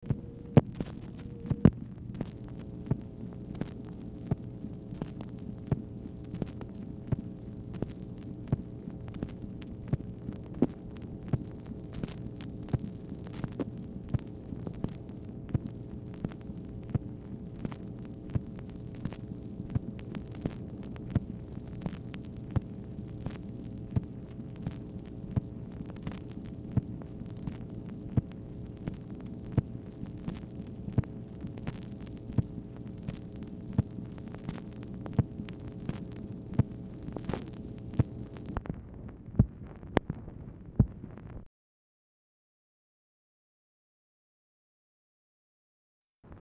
Telephone conversation # 8549, sound recording, MACHINE NOISE, 8/17/1965, time unknown | Discover LBJ
Format Dictation belt
Specific Item Type Telephone conversation